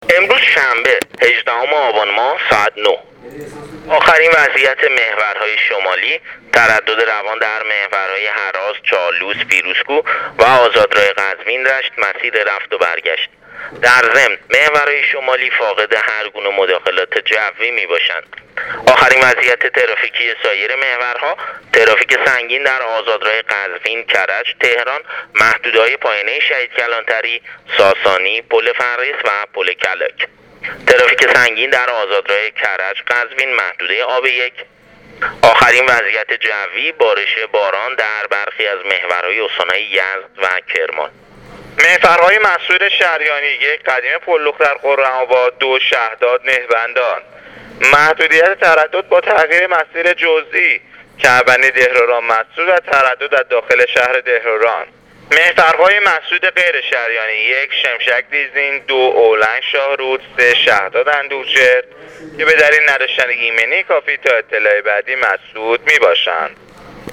گزارش رادیو اینترنتی وزارت راه و شهرسازی از آخرین وضعیت ترافیکی جاده‌های کشور تا ساعت ۹ هفدهم آبان ۱۳۹۸/ تردد روان در محورهای شمالی کشور/ ترافیک سنگین در آزادراه قزوین-کرج-تهران